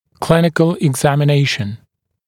[‘klɪnɪkl ɪgˌzæmɪ’neɪʃ(ə)n] [eg-][‘клиникл игˌзэми’нэйшн] [эг-]клиническое обследование